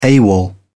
ฟังเสียงคำว่า AWOL